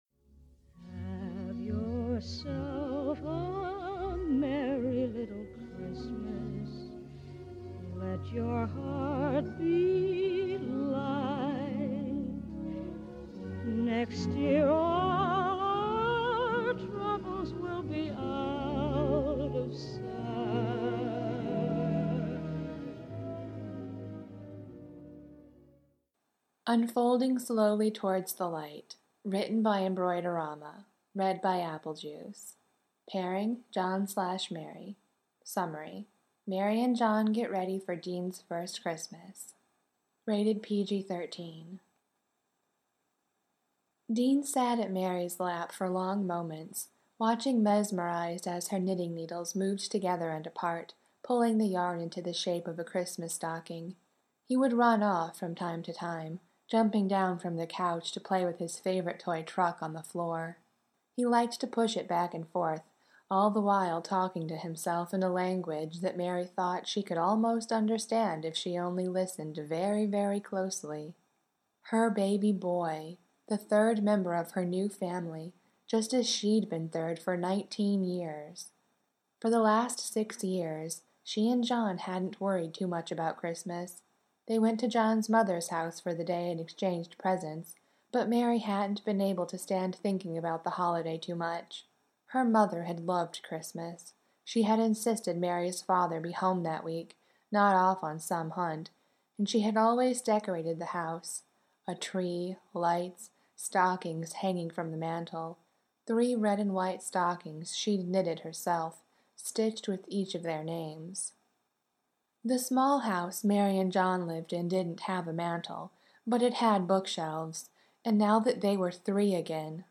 \o/ That’s 27 individual podfics!
01 Unfolding Slowly Towards the Light [with music] mp3.mp3